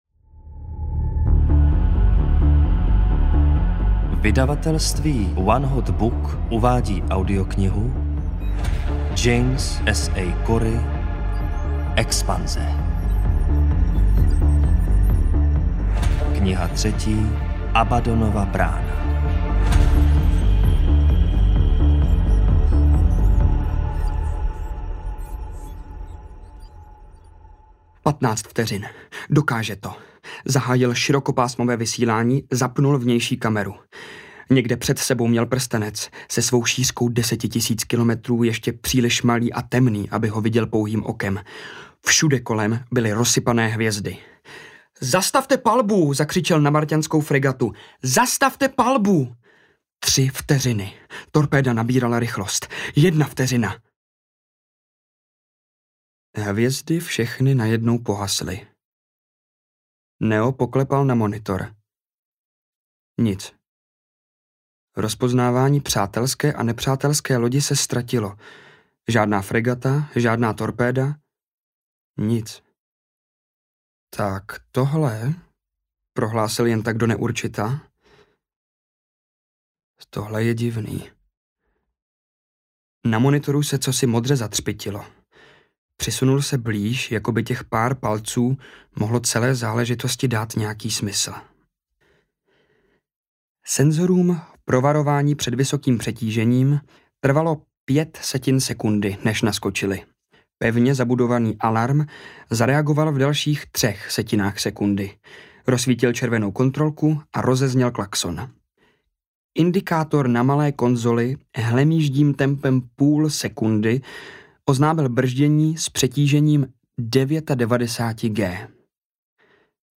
Abaddonova brána audiokniha
Ukázka z knihy